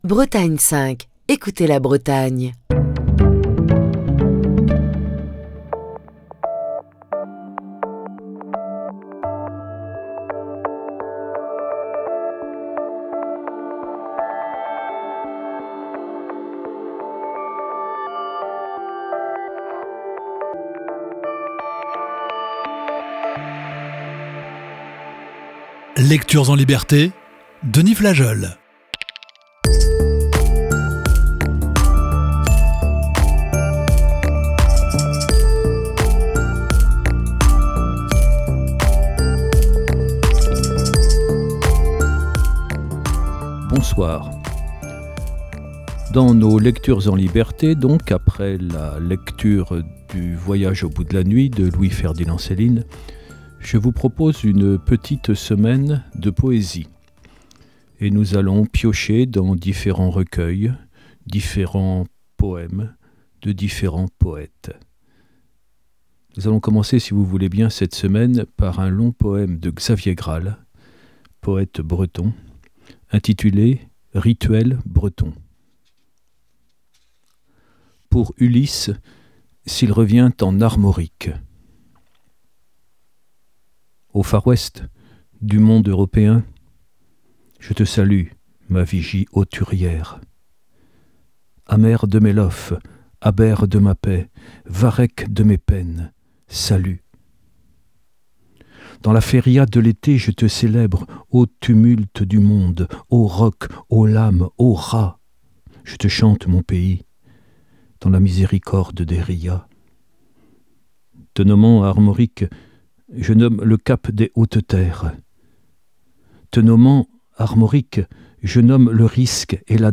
Émission du 5 février 2024.